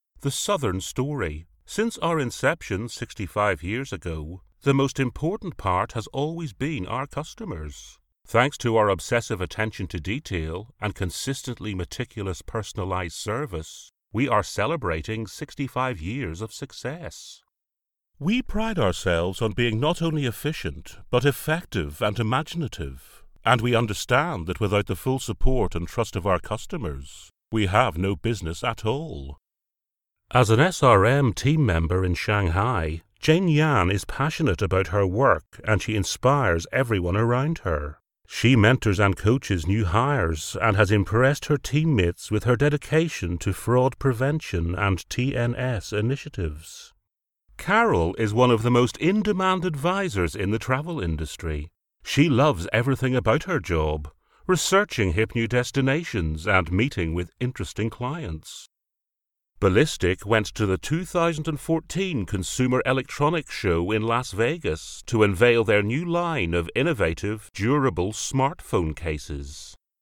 Narration